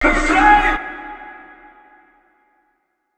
TS Vox_4.wav